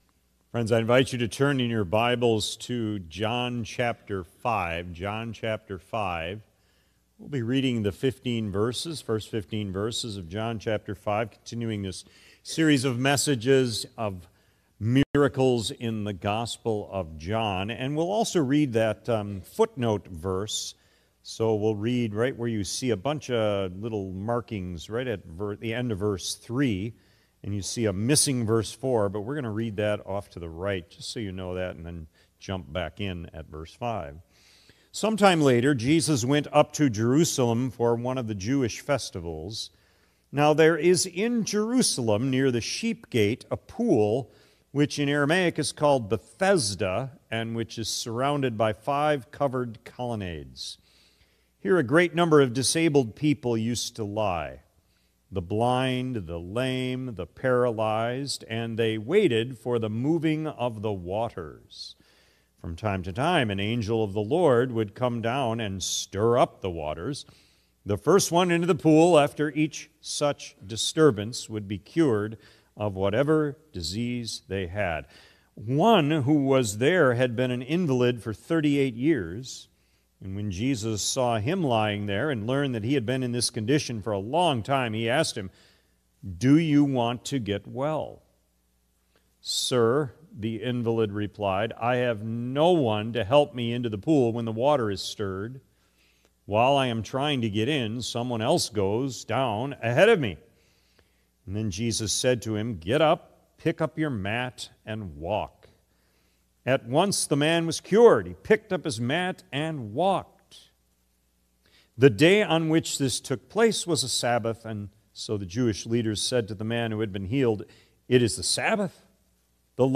“Poolside Paralysis” January 25 2026 P.M. Service
In this sermon on John 5:1-15, we’re reminded that Jesus doesn’t just heal bodies—He confronts hearts.